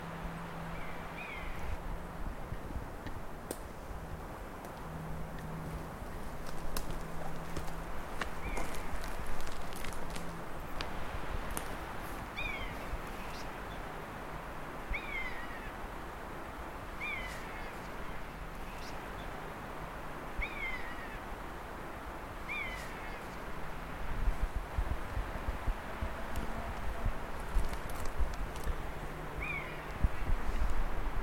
Buzzard [an clamhán]
The distinctive 'mewing' call of a pair of buzzards circling over a conifer wood.